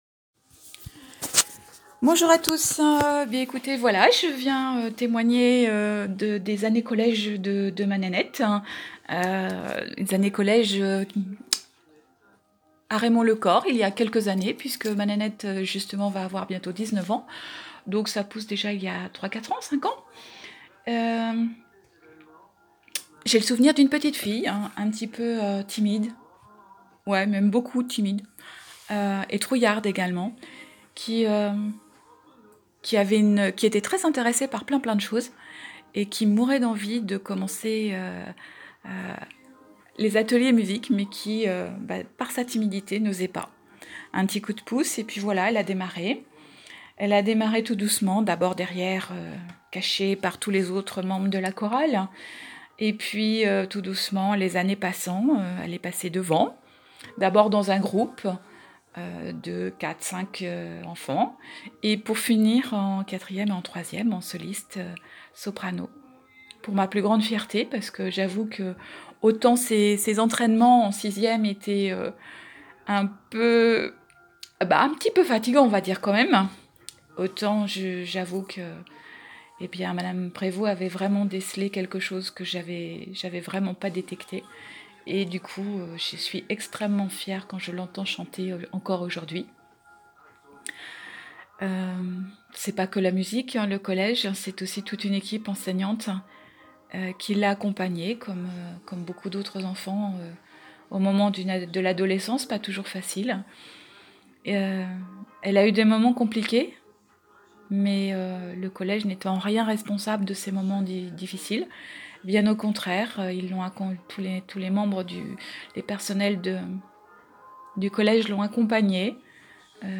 Une ancienne mère d'élève et une ancienne élève témoignent de leur passage ou du passage de leur enfant par le collège Raymond Le Corre.